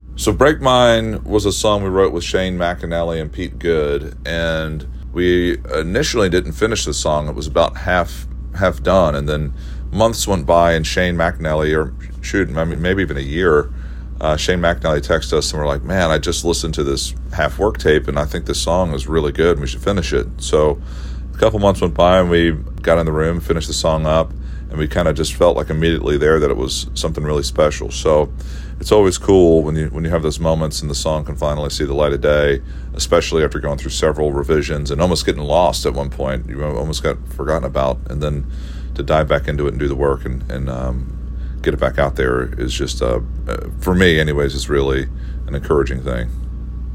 Brothers Osborne's TJ Osborne talks about how the duo almost forgot about the song, "Break Mine."